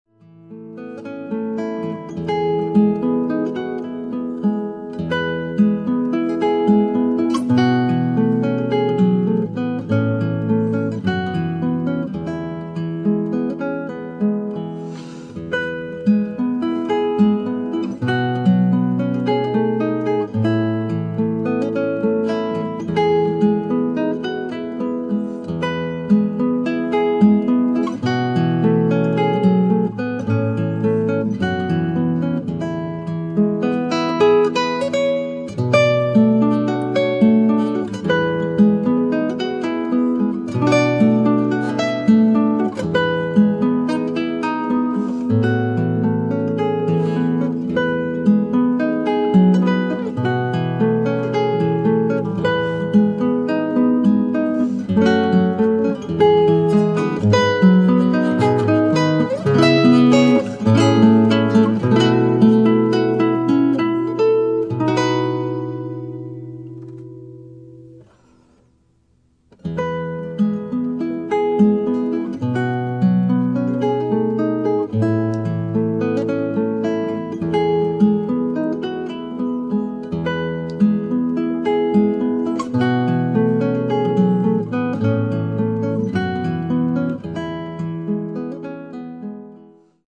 為當代最傑出的新佛朗明哥吉他大師